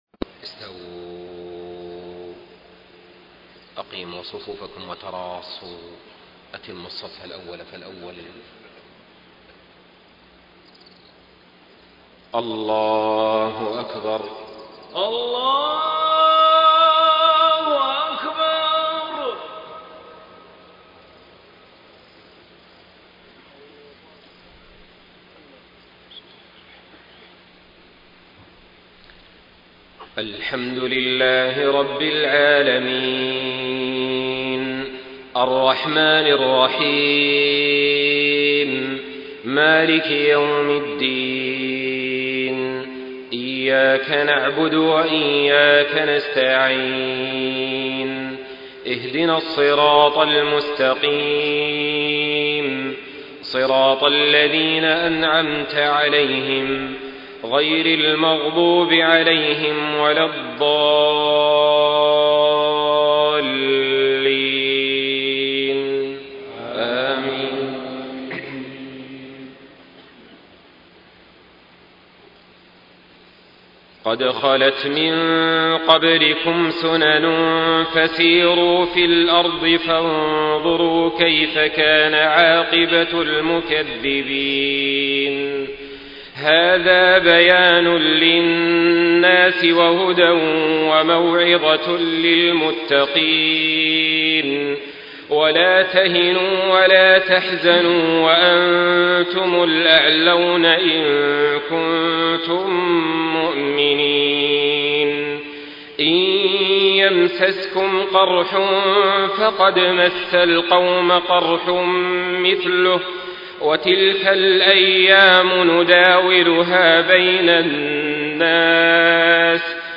صلاة العشاء ١ محرم ١٤٣٤هـ من سورة آل عمران | > 1434 🕋 > الفروض - تلاوات الحرمين